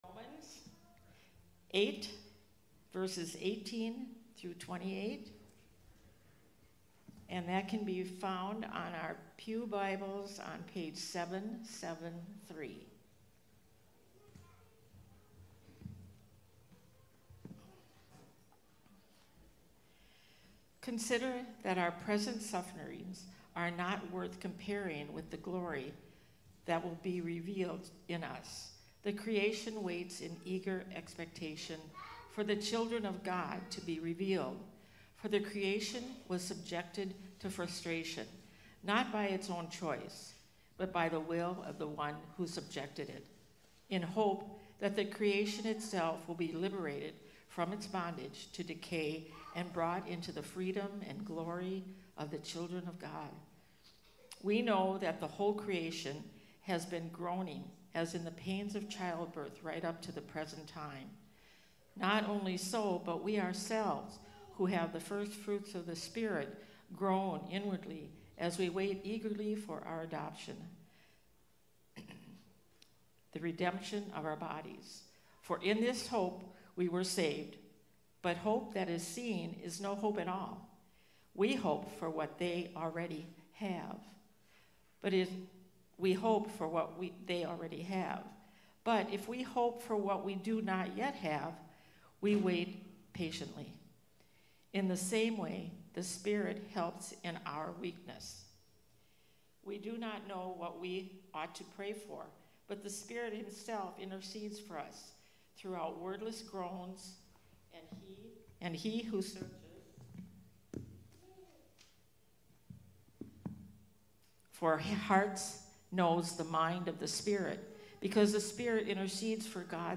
This sermon explores a faith that eagerly anticipates Christ's second coming.